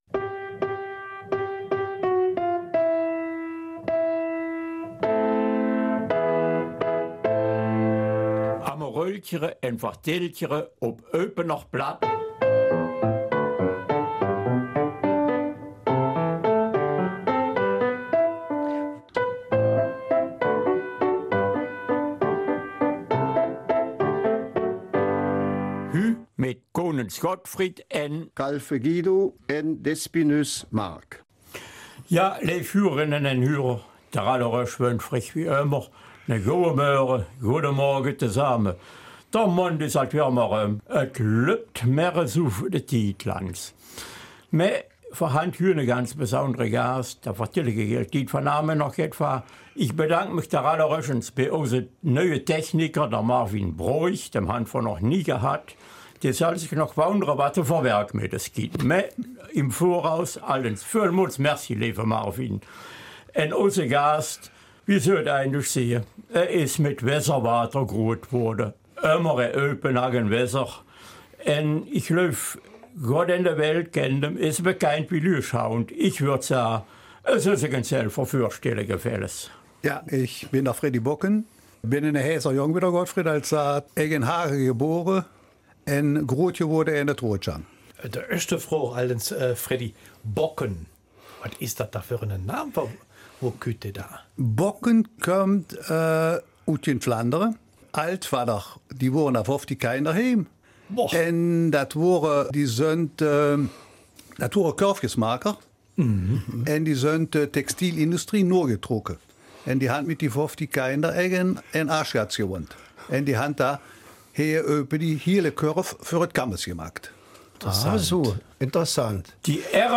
Eupener Mundart: Den kennt doch ''Jedermann''